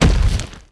troll_commander_walk_left.wav